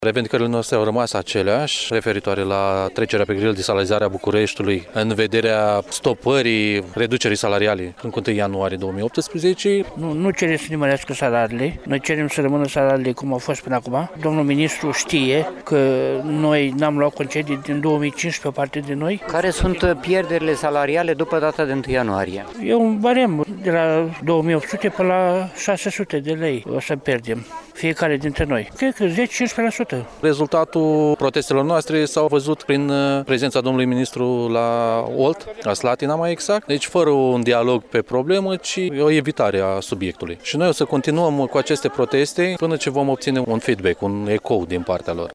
20-nov-rdj-12-vox-pop-protest.mp3